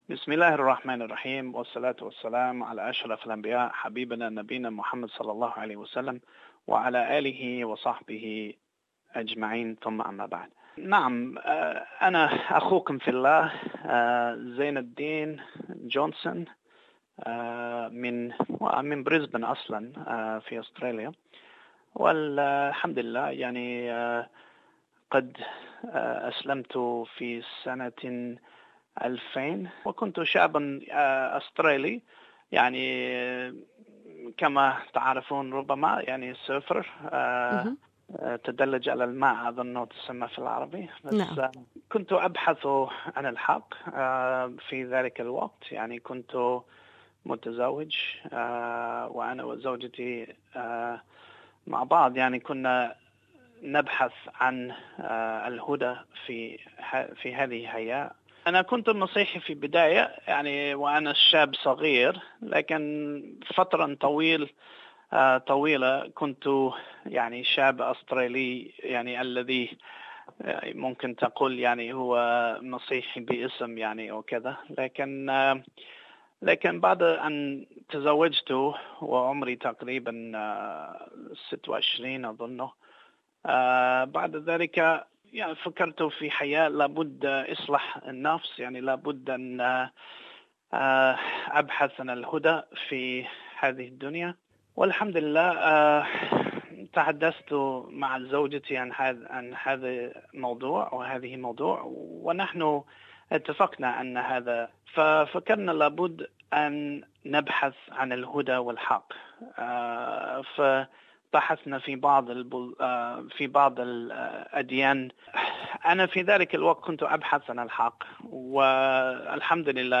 In a wide ranging conversation